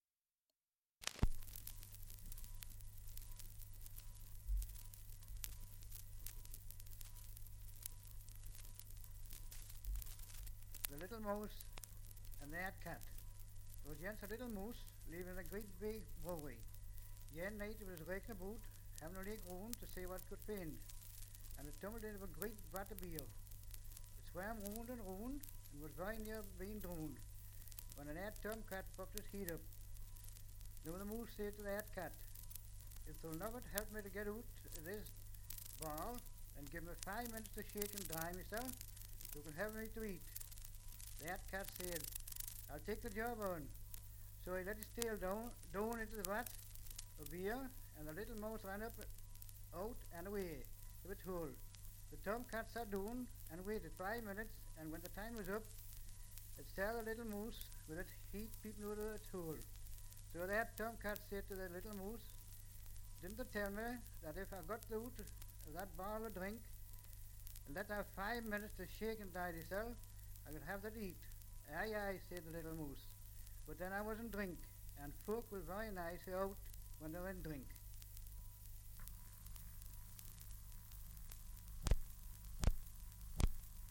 Dialect recording in Ovingham, Northumberland
78 r.p.m., cellulose nitrate on aluminium
English Language - Dialects